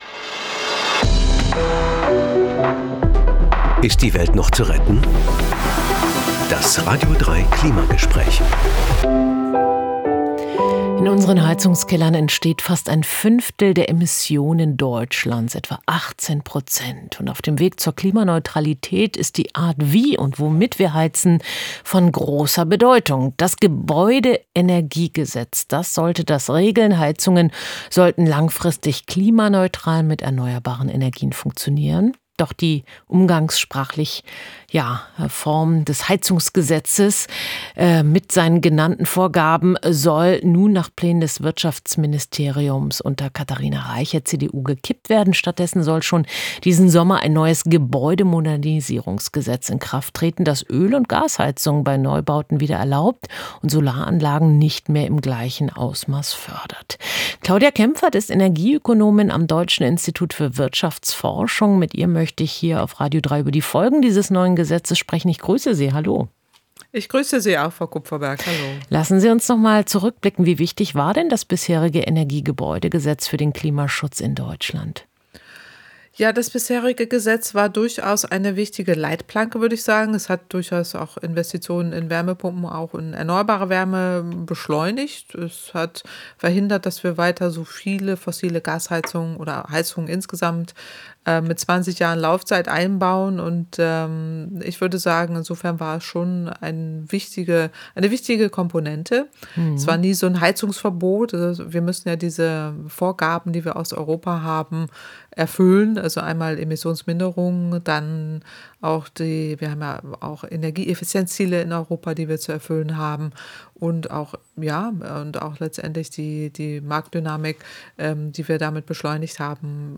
Klimagespräch mit der Energieökonomin Claudia Kemfert vom Deutschen